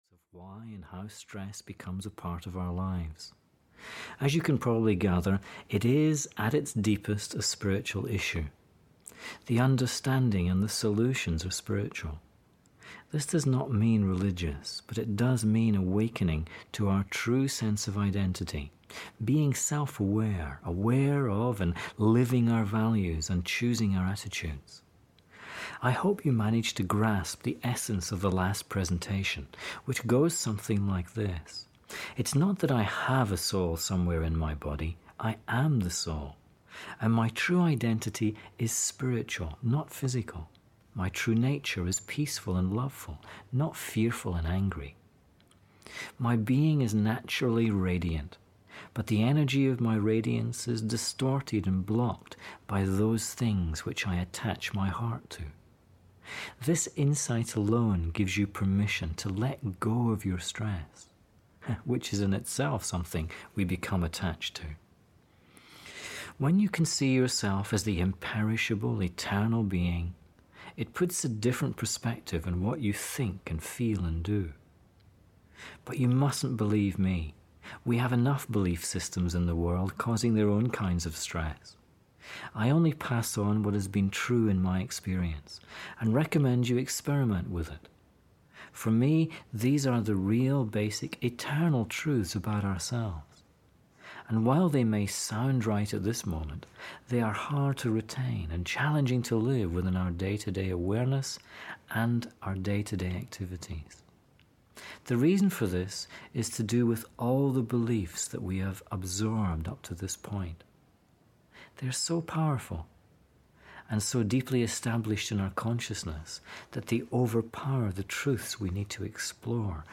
Stress Free Living 3 (EN) audiokniha
Ukázka z knihy